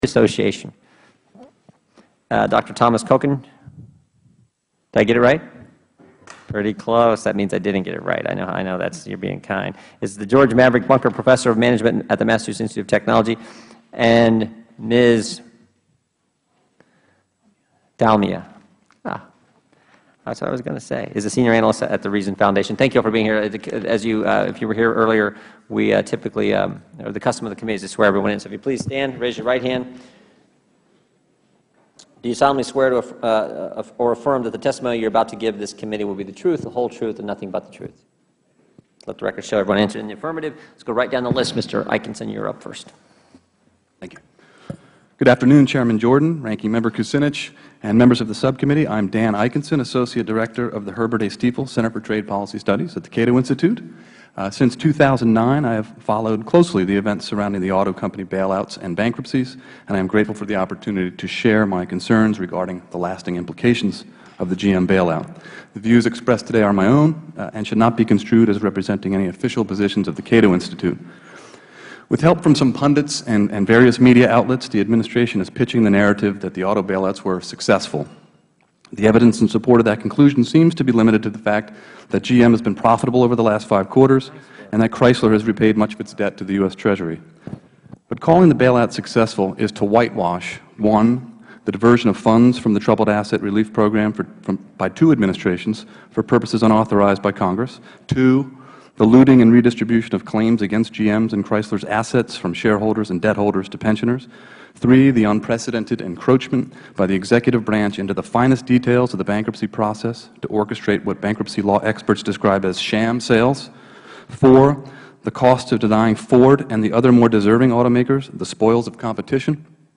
The hearing examined the aftermath of U.S. taxpayers' bailout of General Motors and sought to learn lessons about the lasting implications and unintended consequences of the federal government's intervention into business operations.